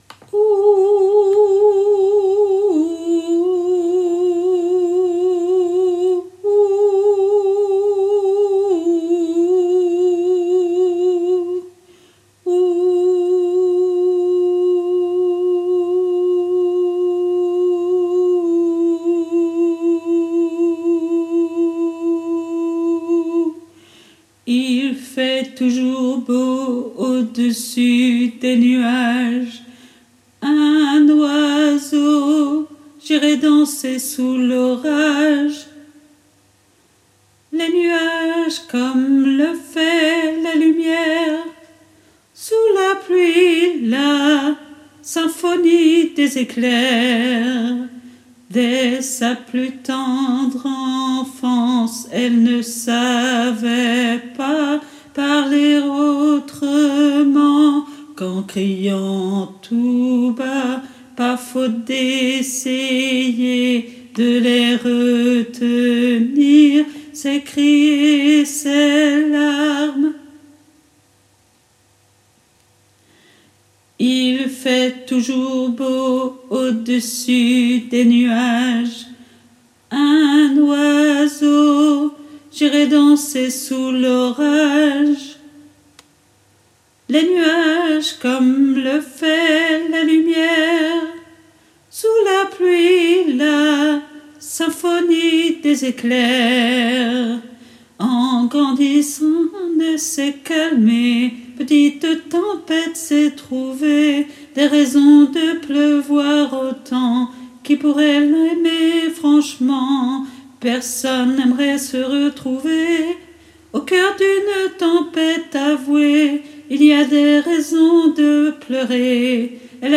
MP3 versions chantées (les audios peuvent être téléchargés)
Voix 2 (alto et basse)